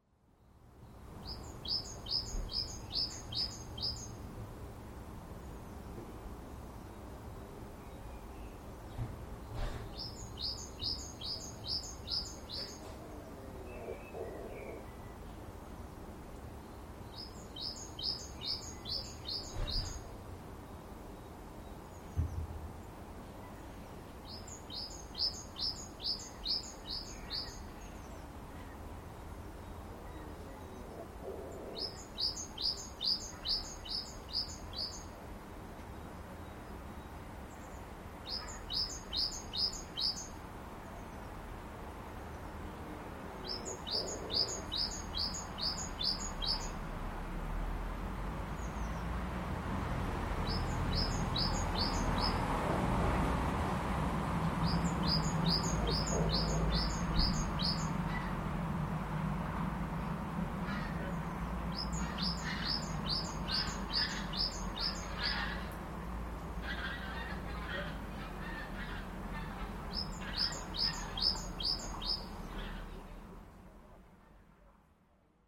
klustermainen (sointumainen) tiivistys, ja vielä kahden sävelen kuin improvisoitu yhdistelmä, joka lopuksi palautuu tutumpaan versioon (taustalla sinitiainen ja aluksi myös viherpeippo).
talikluster.mp3